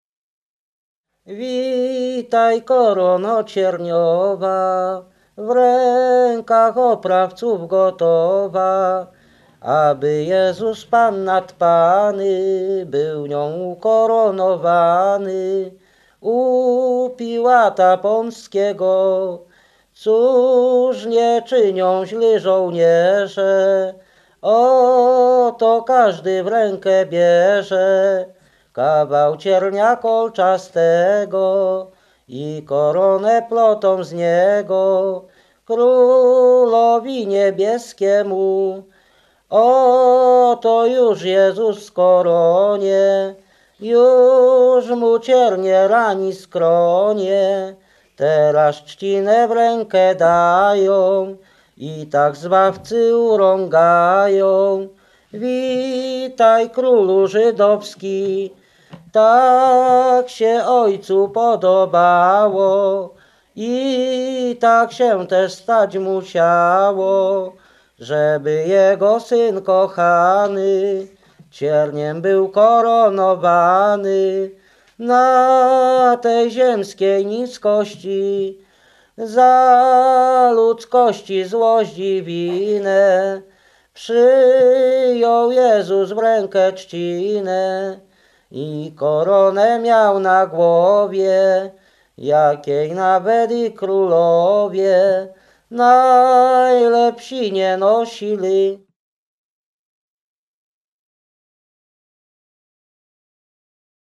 województwo mazowieckie, powiat przysuski, gmina Przysucha, wieś Skrzyńsko
Wielkopostna
wielkopostne nabożne katolickie